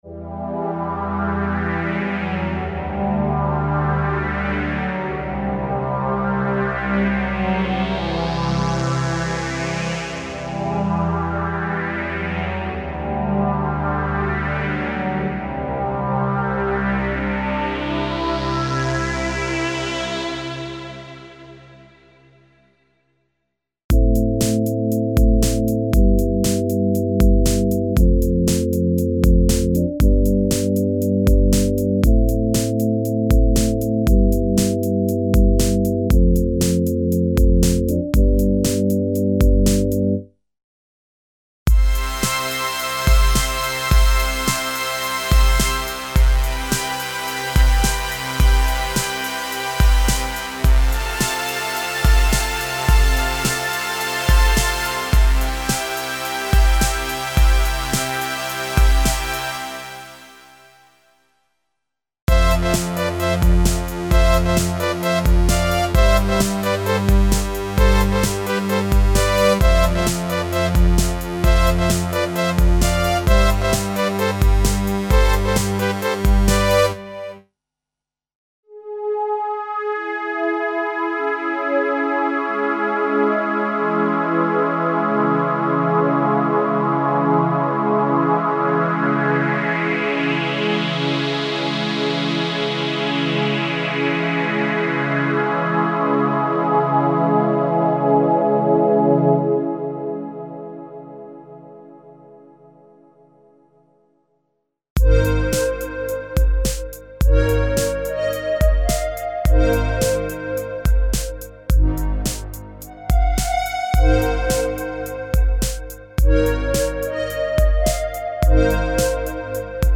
Emulations of vintage analog synthesizers (warm and sharp synth pads & sweeps).
Info: All original K:Works sound programs use internal Kurzweil K2500 ROM samples exclusively, there are no external samples used.